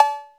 808-Cowbell2.wav